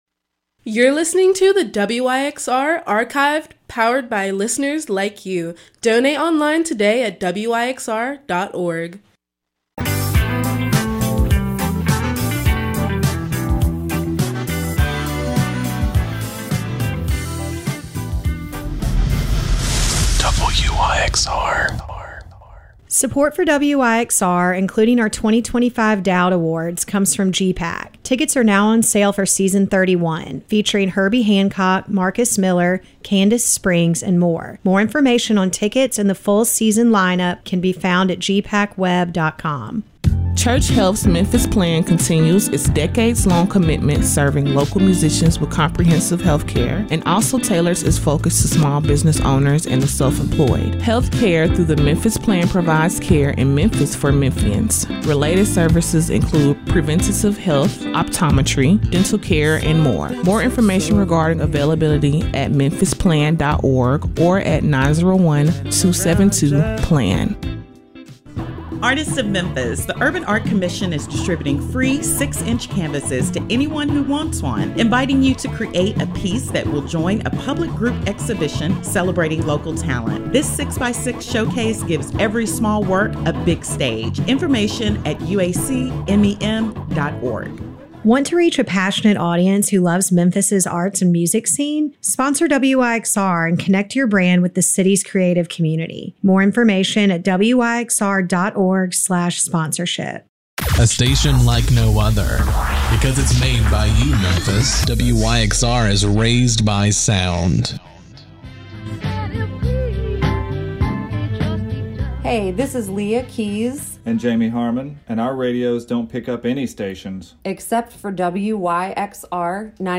During their university spotlight hour, music and conversation gets to the heart of the student voice.
Hip Hop Rhythm and Blues